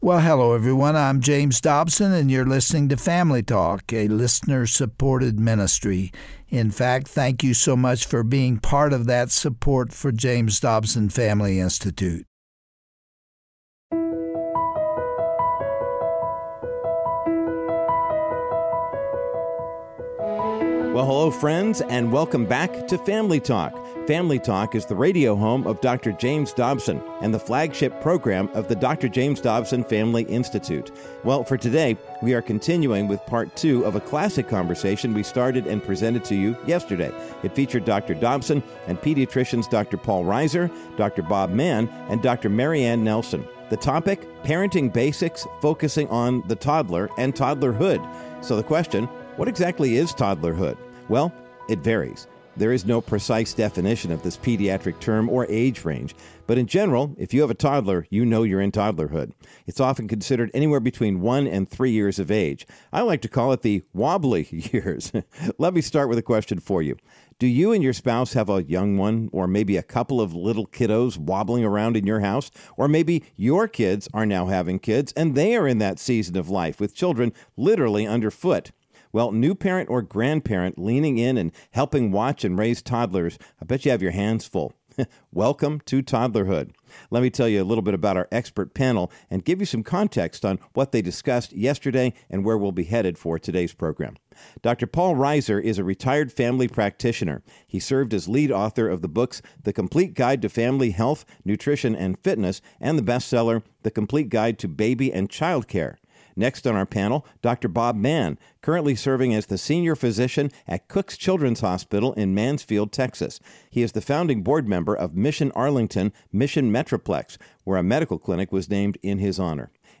On today’s edition of Family Talk, Dr. James Dobson continues speaking with a panel of pediatricians about the toddler years.